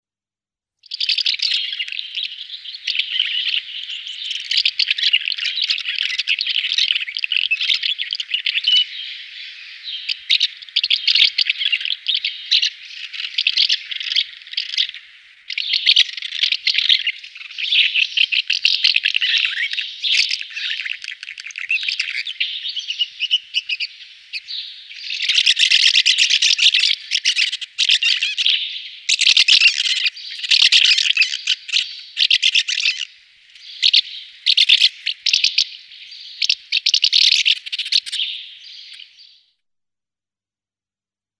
Chant :
Hirondelle des fenêtres
L'hirondelle de fenêtre gazouille. Elle émet un chant très calme "tchirp" "tshirrip". A la voix, on la distingue facilement de l'hirondelle rustique. Son chant ne se compose que d'une phrase lente, uniforme, et qu'elle répète plusieurs fois.
26HouseMartin.mp3